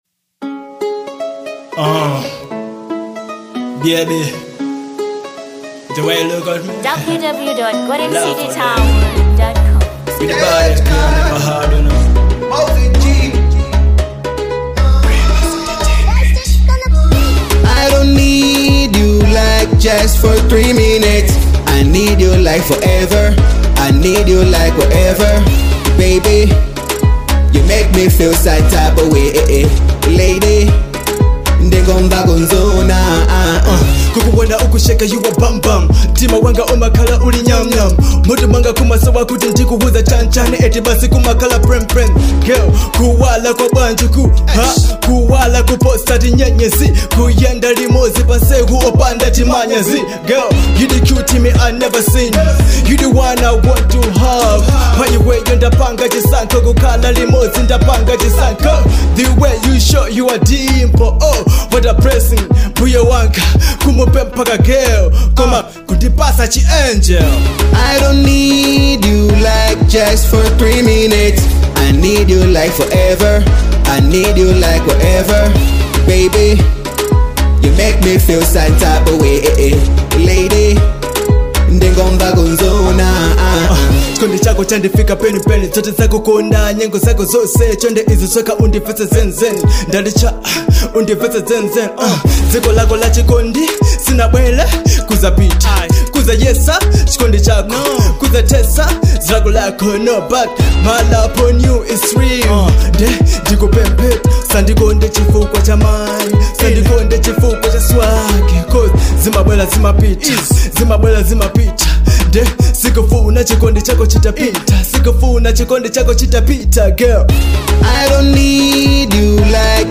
2. Afro Pop